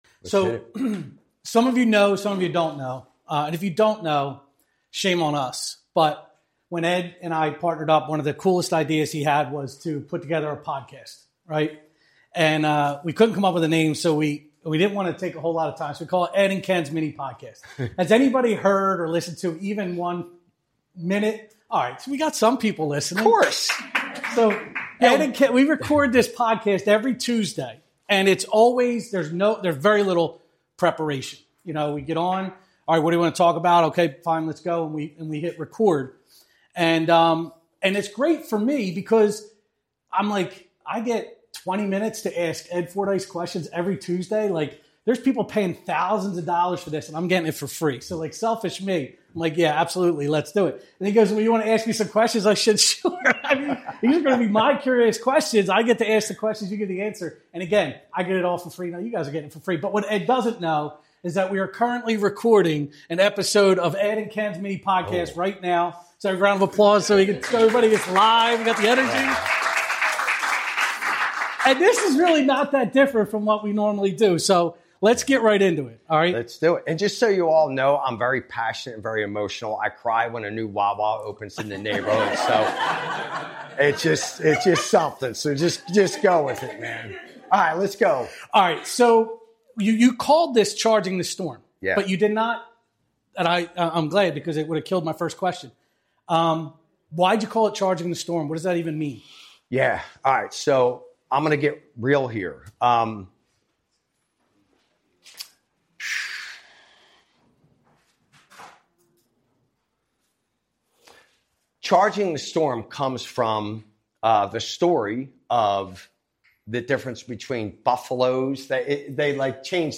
Special Live Episode!